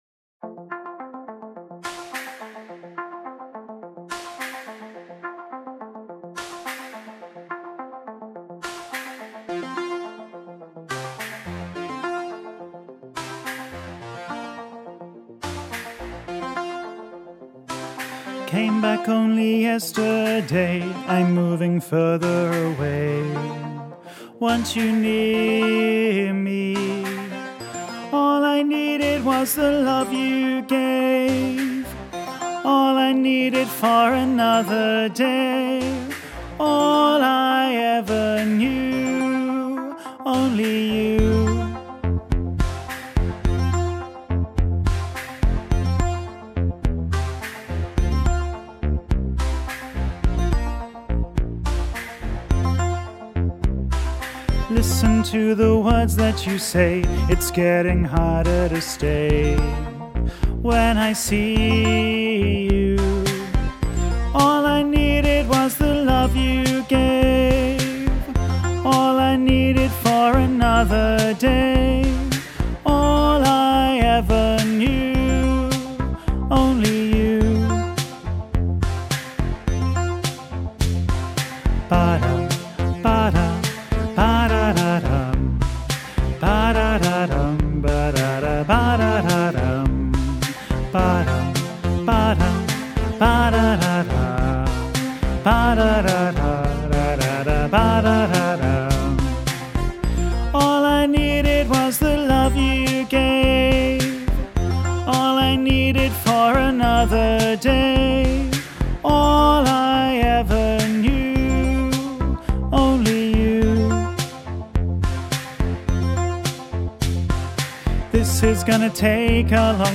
3-lower-part-men-only-you.mp3